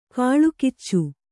♪ kāḷukiccu